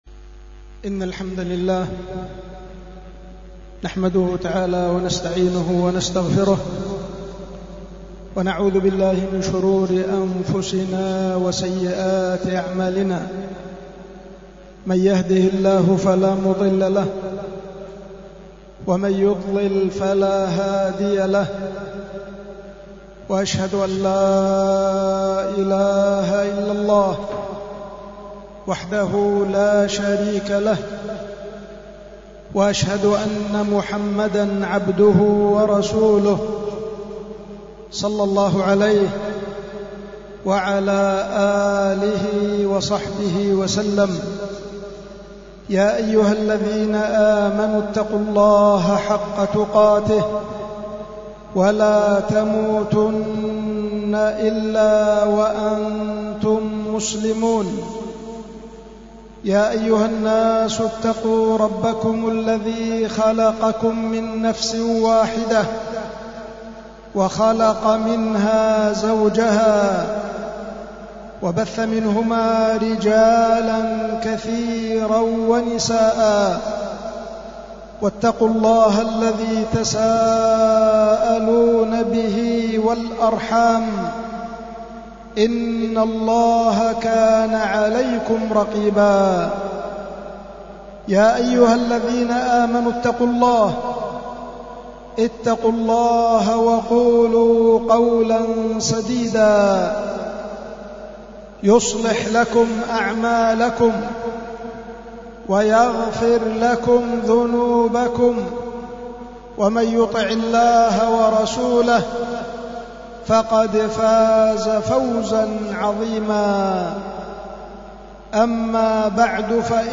خطبة الجمعة
القيت في جامع الفردوس في مدينة عدن